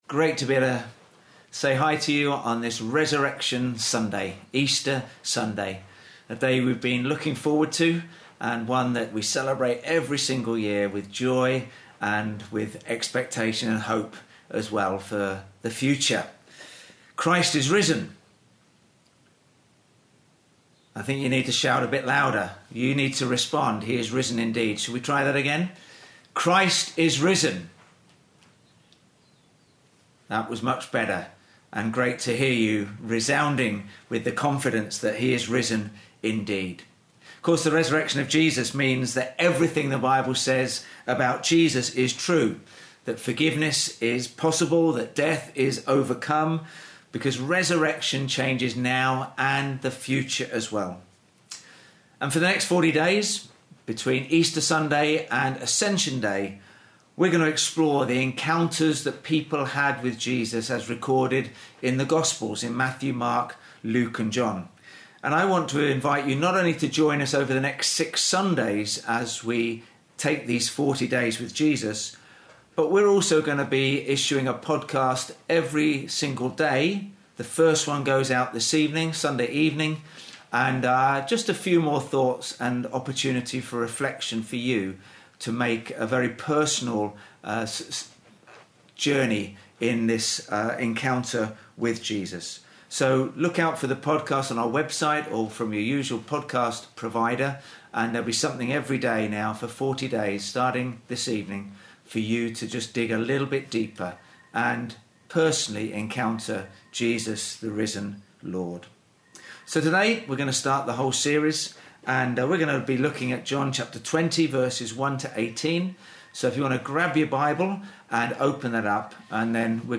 Bible Text: John 20:1-18 | Preacher